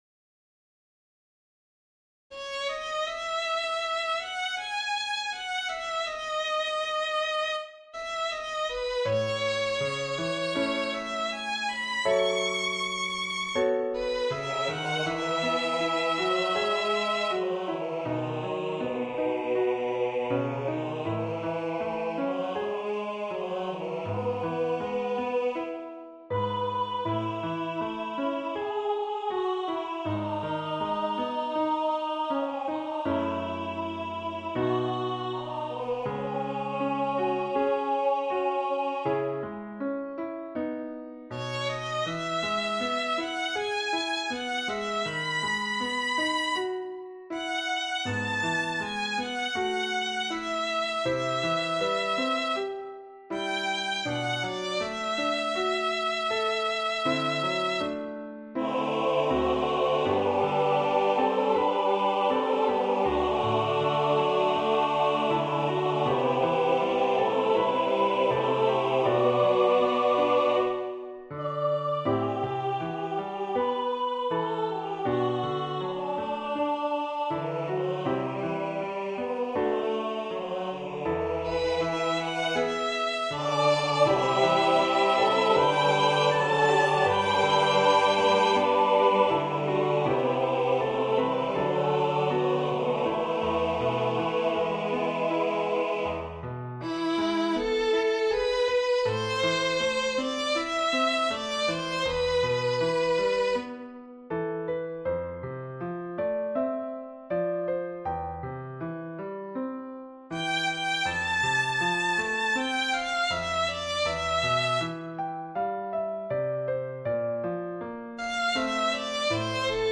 Voicing/Instrumentation: SATB
Choir with Soloist or Optional Soloist Violin Optional Obbligato/Violin Accompaniment